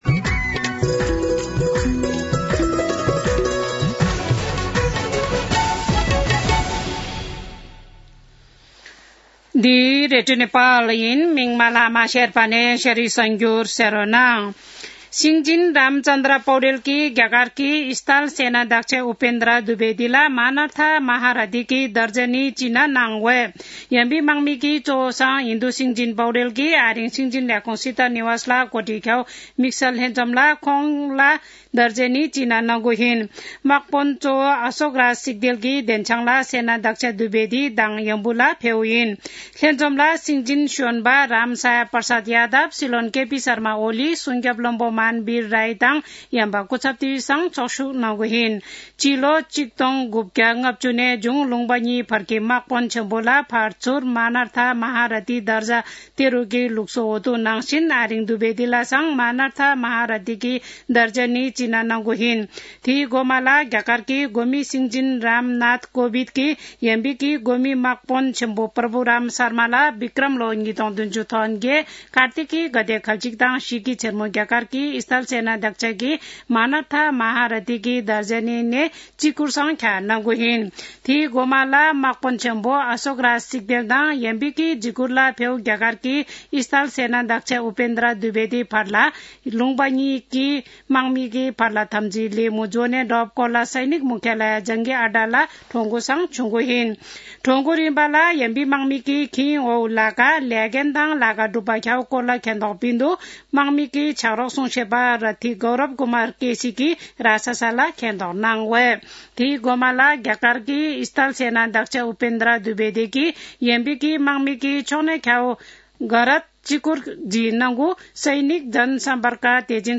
शेर्पा भाषाको समाचार : ७ मंसिर , २०८१
4-pm-Sherpa-news-1-2.mp3